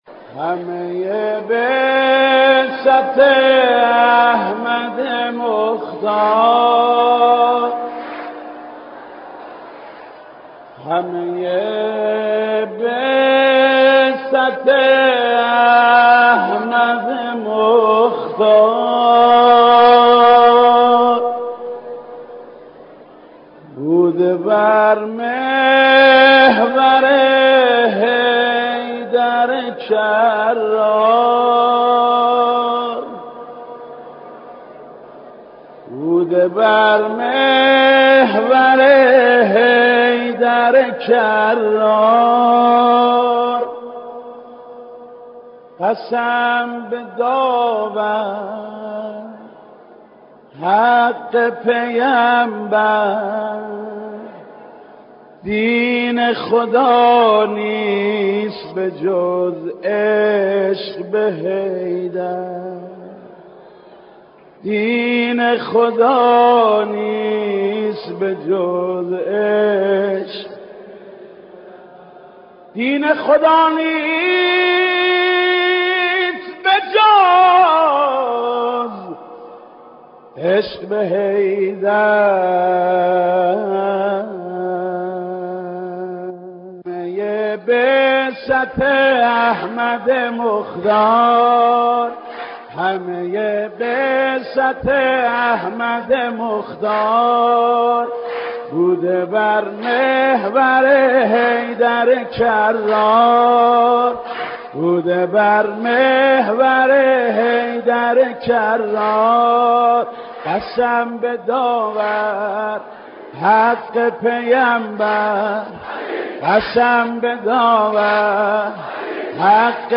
مولودی های مناسب عید مبعث با صدای مداحان مشهور